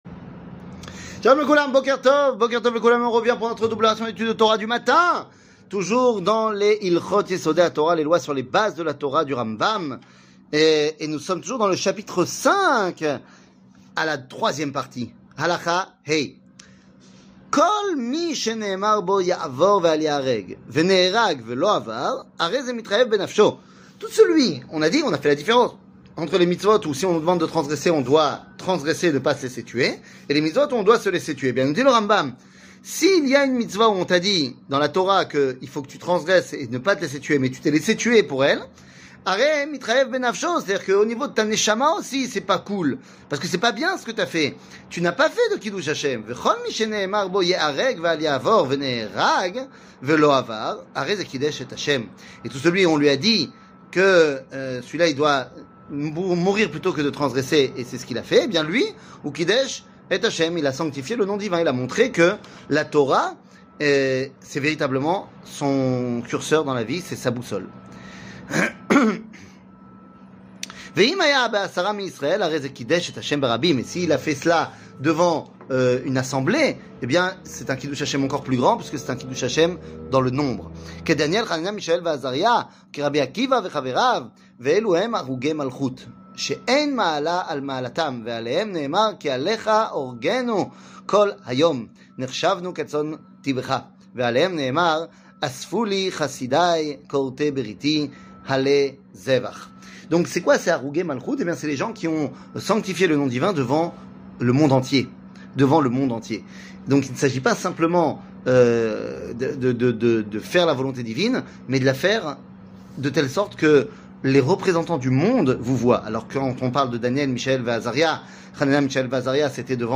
שיעור מ 06 מרץ 2024
שיעורים קצרים